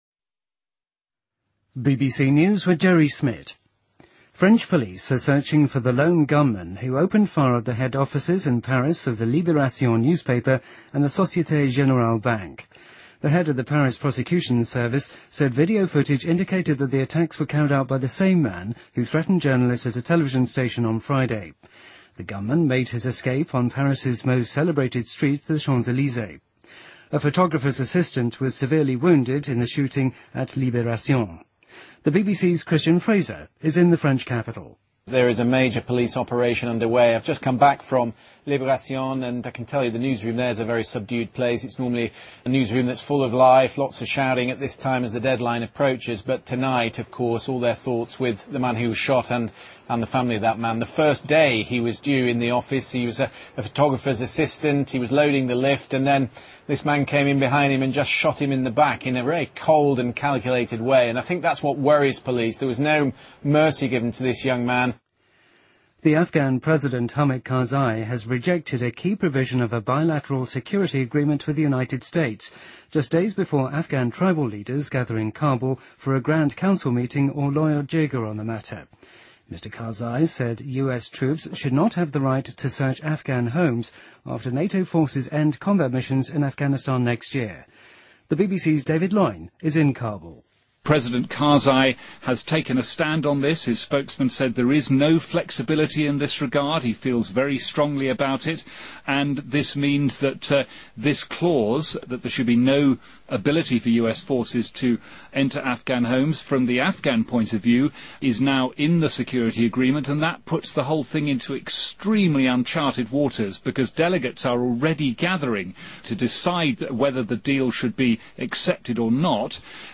BBC news,多伦多的参事们正就进一步剥夺市长罗伯特.福特权力的提议进行辩论